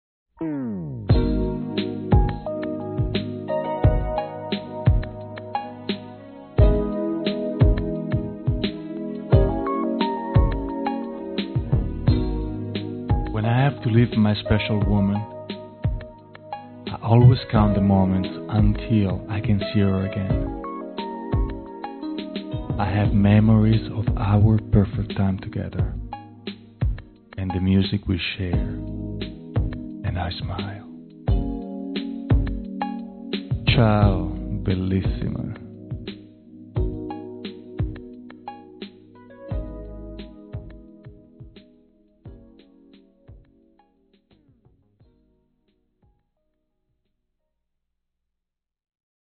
In this last, breathless moment with him, when he's stumbling so adorably through the English language, he leaves us wondering Wait, he has mammaries?